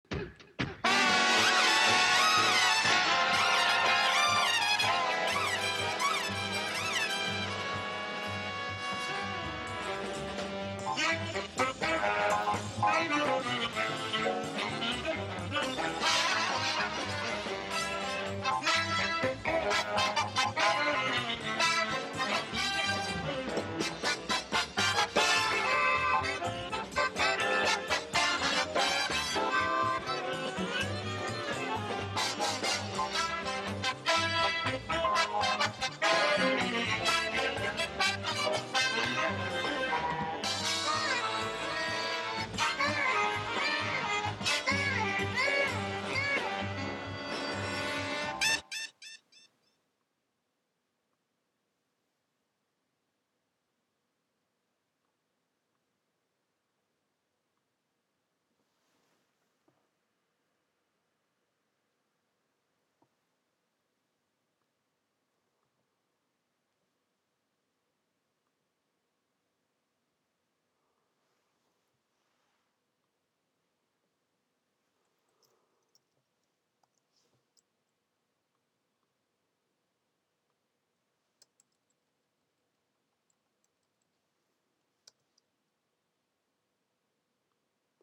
bombastic over the top